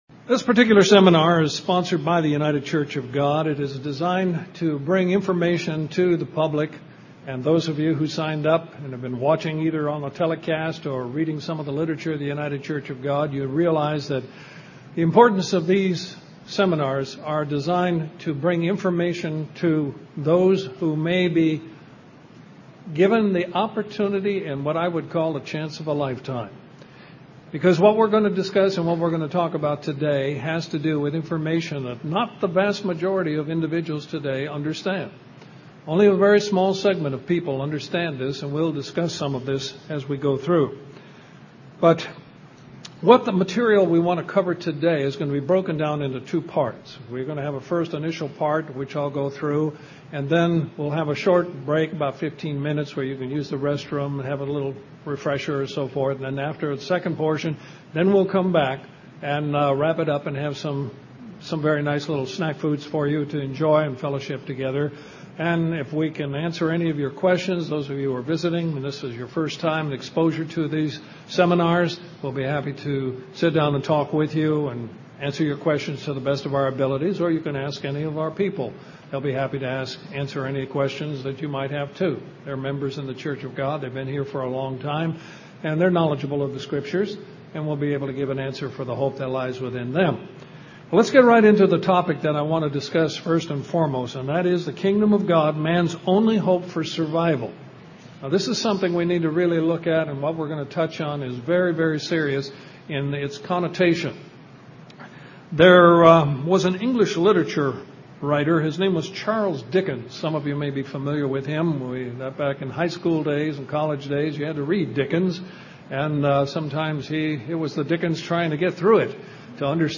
Knowledge of the truth about the Kingdom of God is the chance of a lifetime, as the Kingdom is man's only hope for survival. Learn more in this Kingdom of God seminar.